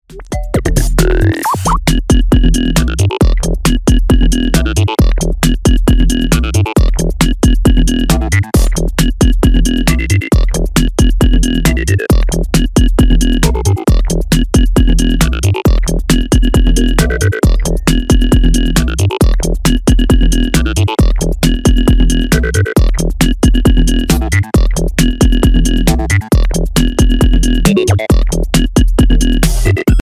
さりげなく複雑で繊細なエフェクトの妙味が完全に新世代の感覚